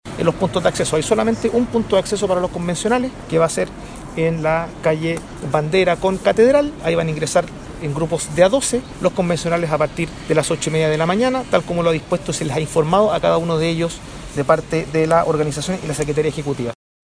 El subsecretario General de la Presidencia, Máximo Pavez, explicó de qué manera ingresarán los convencionales al lugar donde fueron convocados.